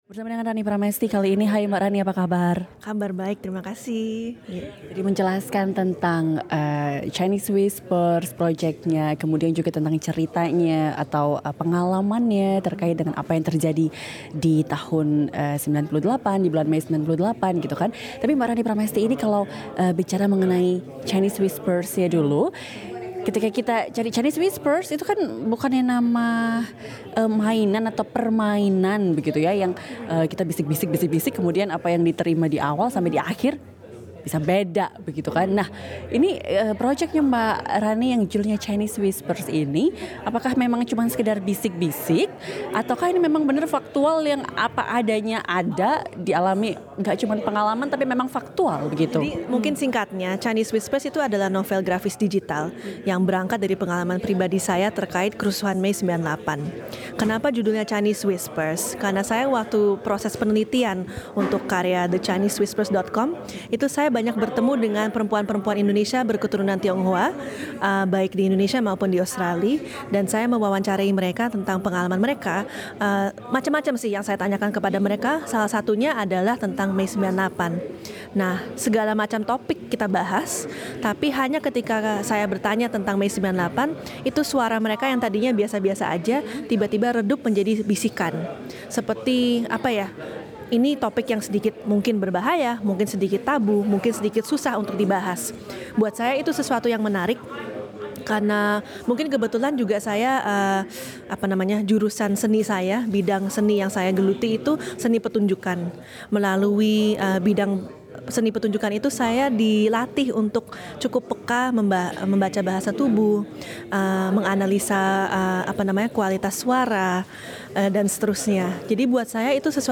Pada hari Minggu 10 Juni 2018, sebuah diskusi terbatas bertajuk 'Peringatan 20 tahun Reformasi Indonesia' dari sudut pandang seni dan ekonomi digelar di Newtown Neighbourhood Centre, lima kilometer dari pusat kota Sydney, Australia.